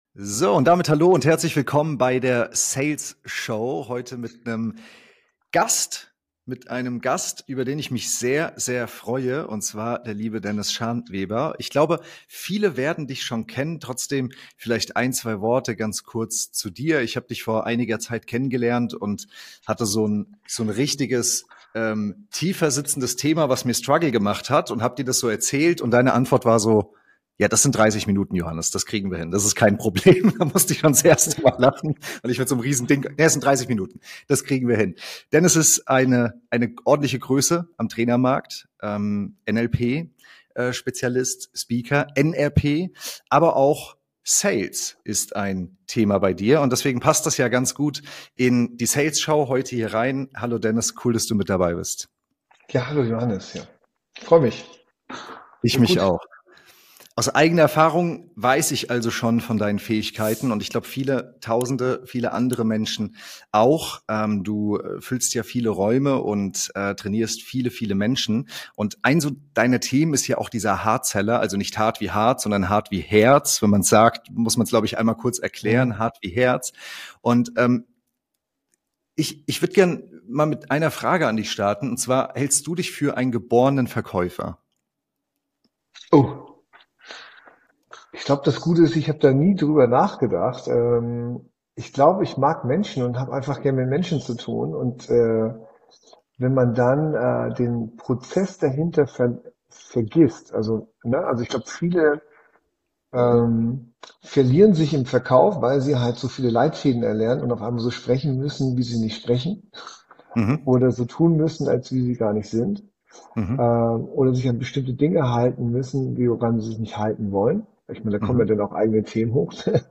#47 Mehr Verkaufen mit DIESEM Ansatz: Sales neu gedacht (Interview